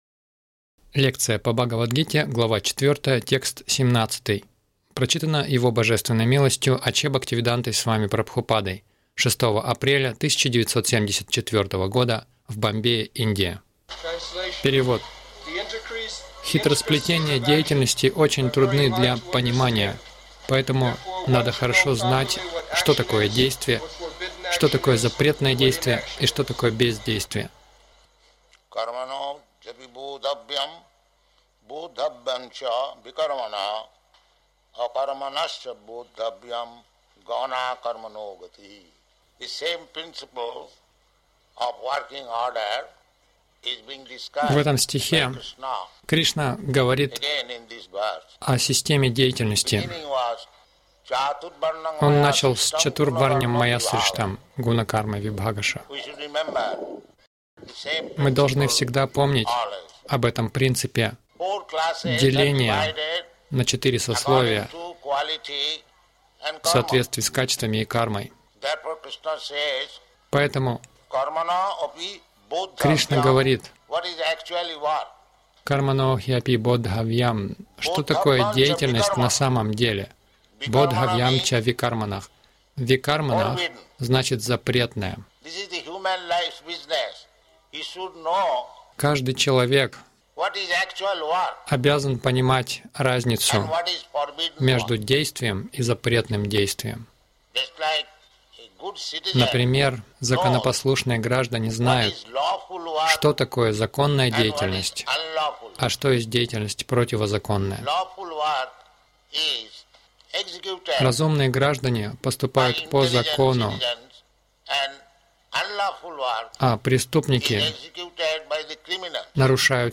Милость Прабхупады Аудиолекции и книги 06.04.1974 Бхагавад Гита | Бомбей БГ 04.17 — Карма, акарма, викарма Загрузка...